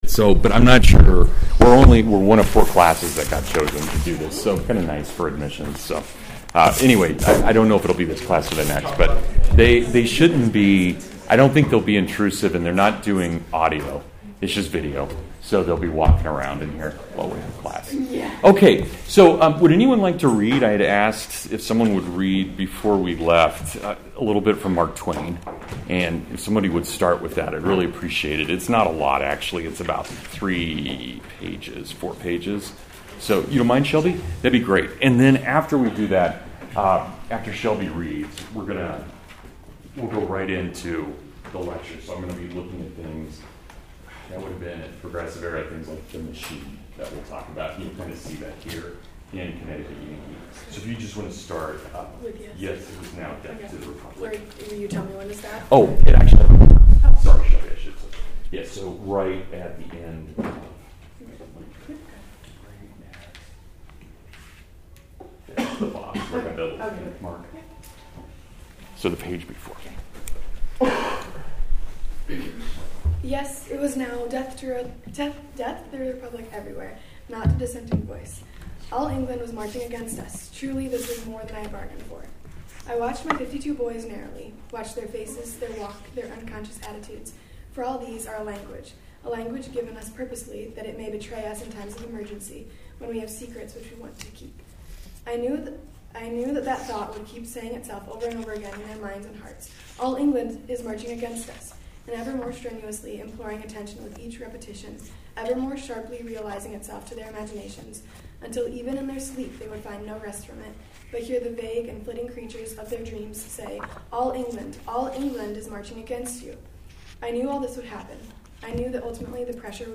The “Machine” of Ideology (Full Lecture)